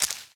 New sound for rock/plant breaking
plantBreak.ogg